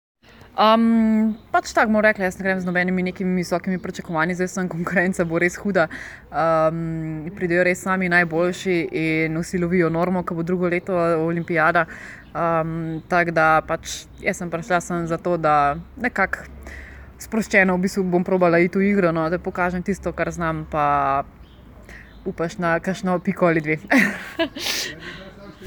Iz slovenskega tabora (avdio izjave